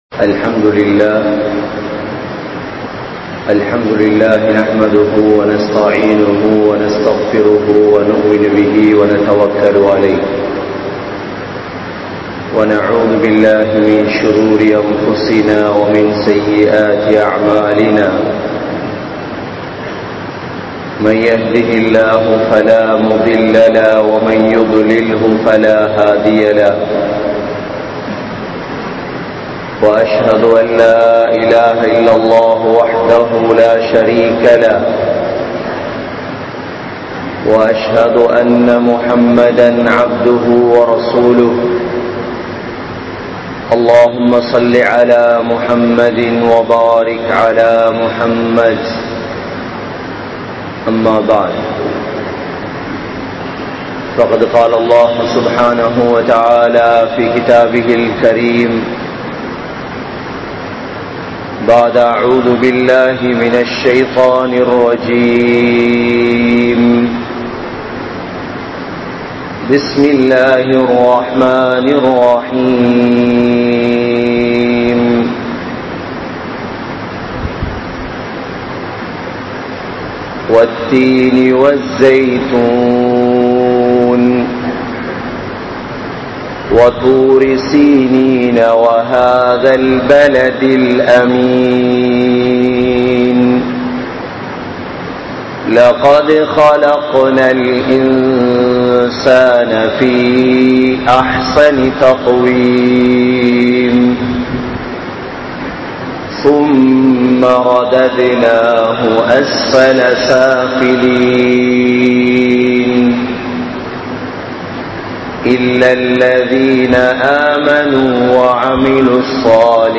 Ilahuvaaha Suvarkatthai Adaiya Mudiyaathu (இலகுவாக சுவர்க்கத்தை அடைய முடியாது) | Audio Bayans | All Ceylon Muslim Youth Community | Addalaichenai
Grand Jumua Masjith